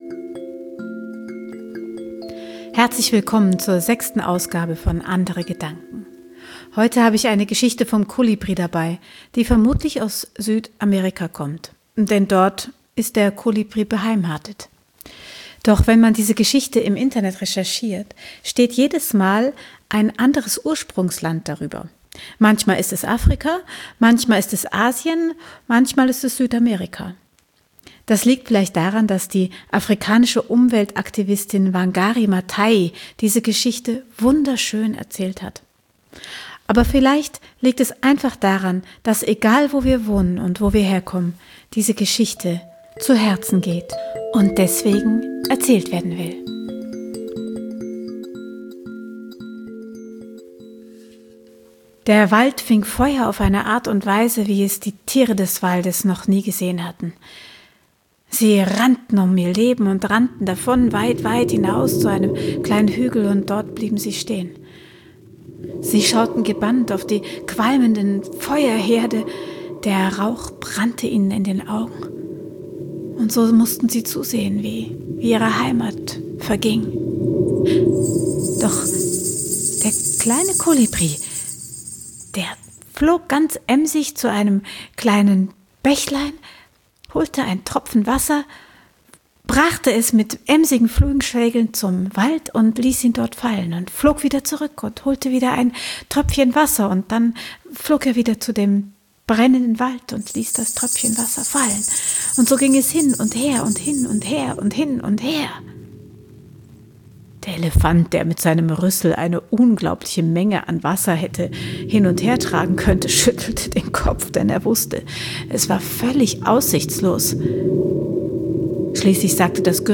frei erzählte Geschichten, Musik, Inspiration